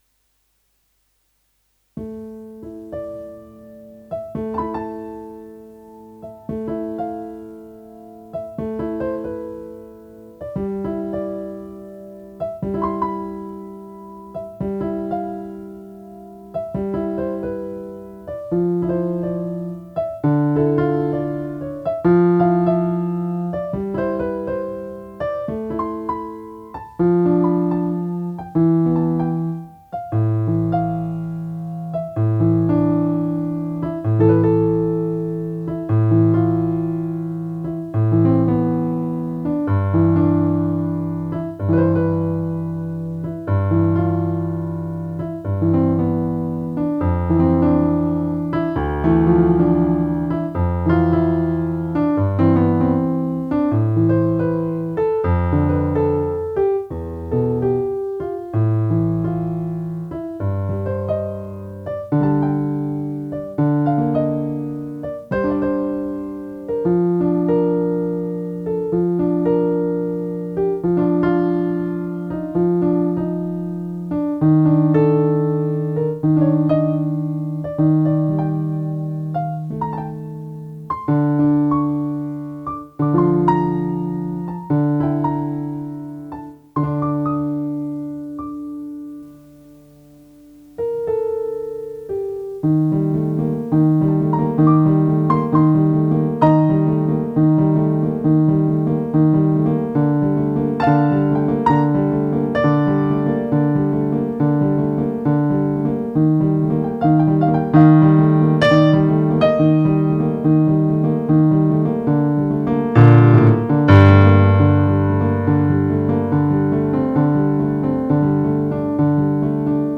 Uansett - her kommer enda en (u)musikalsk oppmuntring fra min side med en ny versjon som jeg spilte inn i natt; det er ikke så mye jeg har endret på, men har gjort slutten lengre, og lagt til tre toner rett før overgangen for å binde de to delene sammen, så selv om jeg min vane tro gjør småfeil her og der, håper jeg likevel dette kan muntre deg litt opp, og at sangen din kan fortsette å spre livsglede: